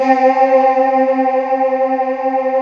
Index of /90_sSampleCDs/USB Soundscan vol.28 - Choir Acoustic & Synth [AKAI] 1CD/Partition D/01-OUAHOUAH